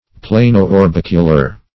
Search Result for " plano-orbicular" : The Collaborative International Dictionary of English v.0.48: Plano-orbicular \Pla"no-or*bic"u*lar\, a. [Plano- + orbicular.] Plane or flat on one side, and spherical on the other.